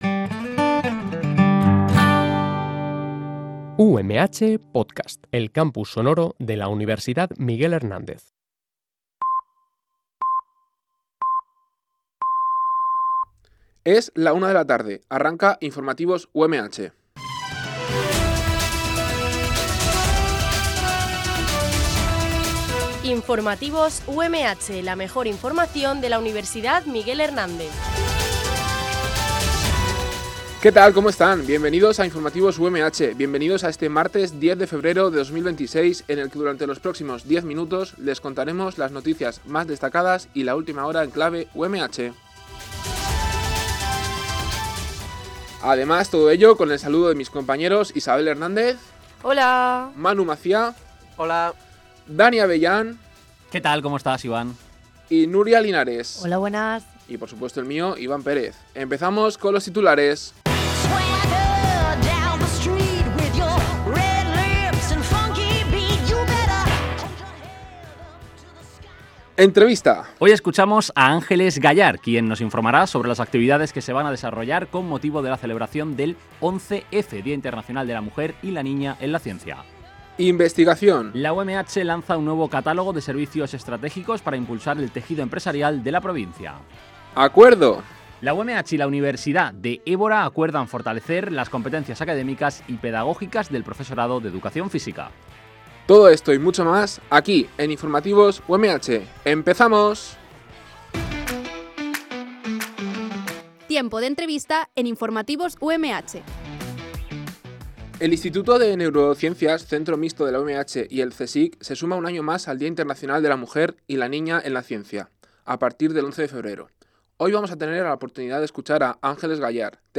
Este programa de noticias se emite en directo, de lunes a viernes, en horario de 13.00 a 13.10 h.